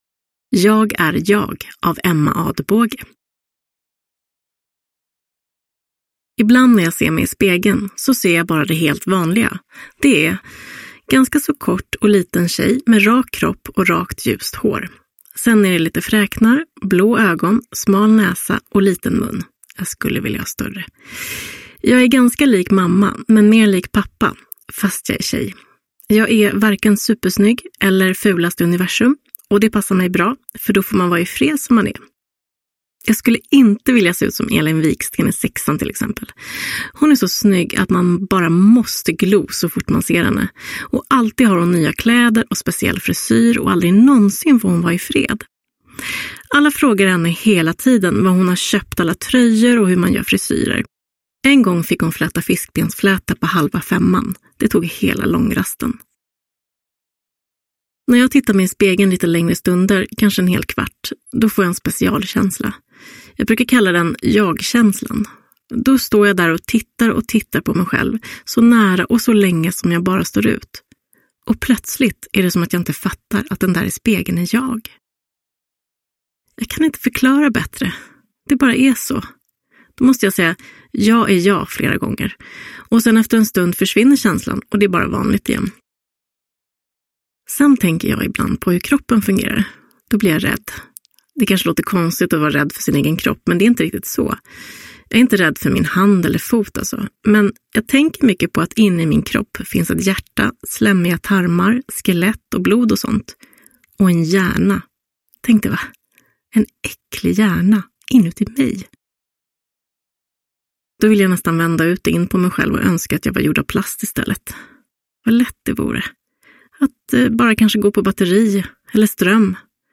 Jag är jag – Ljudbok – Laddas ner